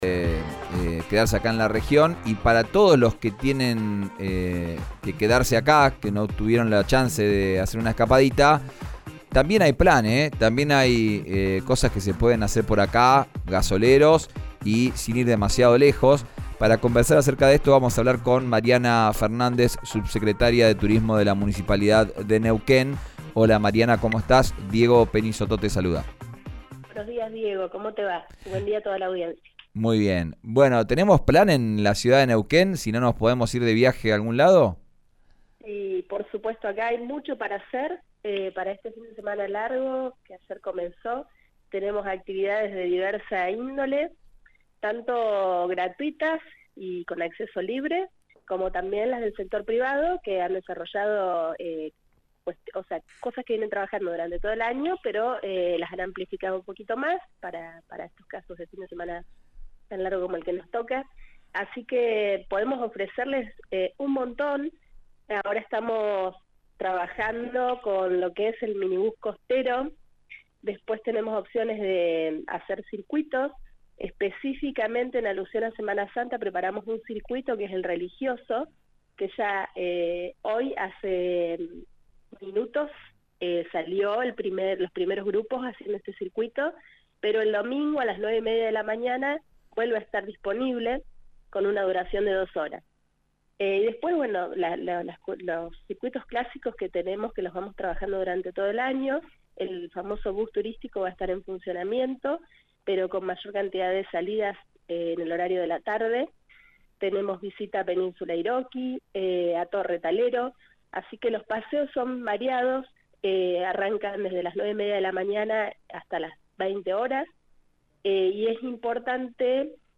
Escuchá a la subsecretaria de Turismo de la Municipalidad de Neuquén, Mariana Fernández, en RÍO NEGRO RADIO: